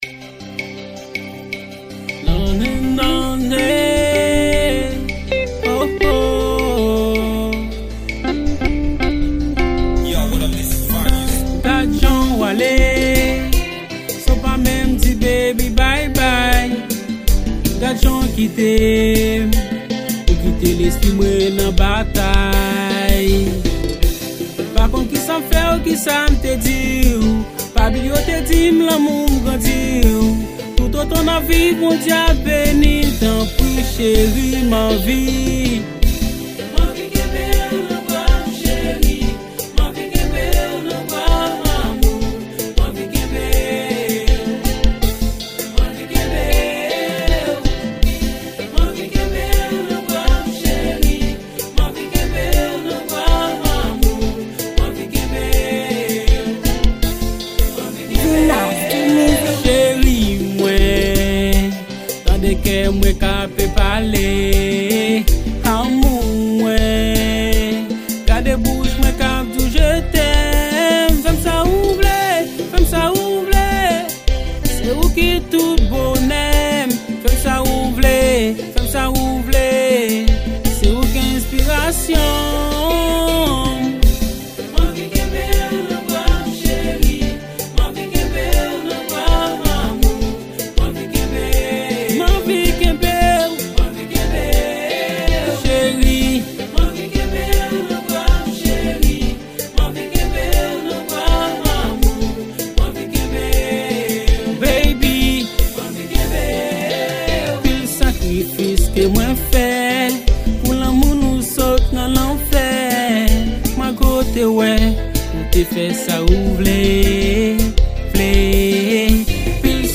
Genre: Compas.